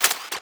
Plasma Rifle
GUNMech_Reload_05_SFRMS_SCIWPNS.wav